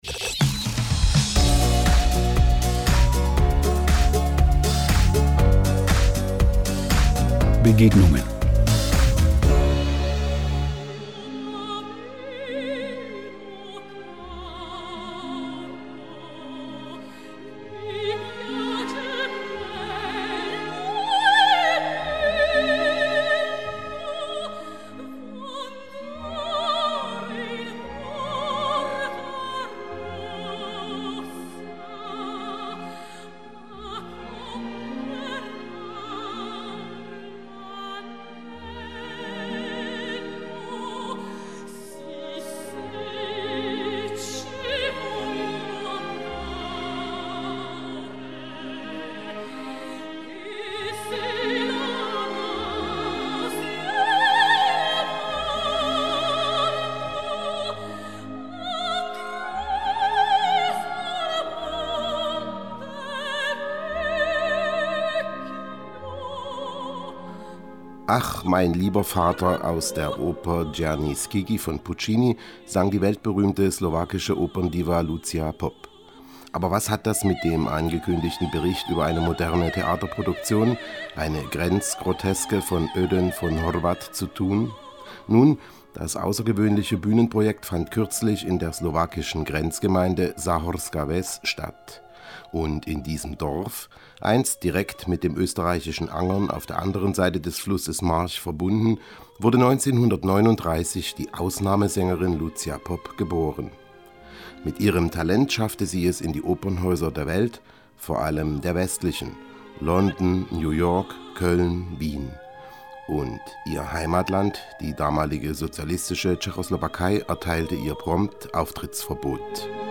Bericht auf Radio Slowakia